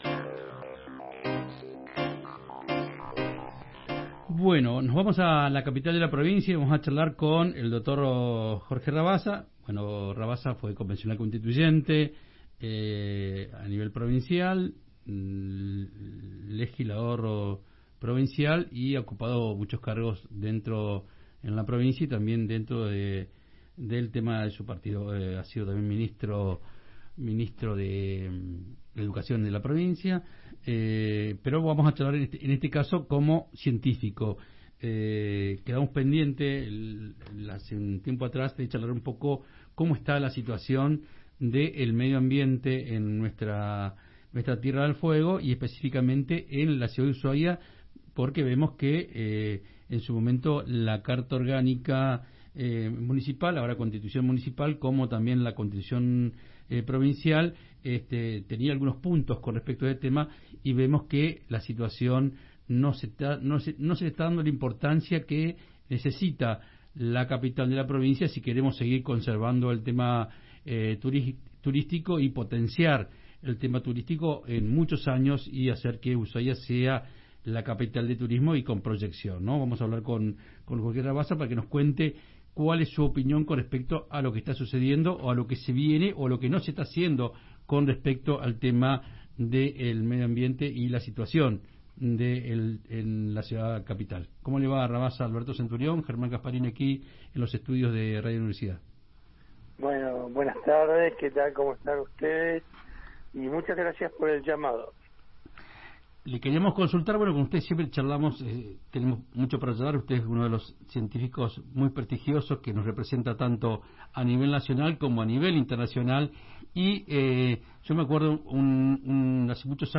explicó por Radio Universidad 93.5 y Provincia 23 el avance del cambio climático y la incidencia de la actividad turística sobre cursos de agua esenciales para la provisión de la ciudad.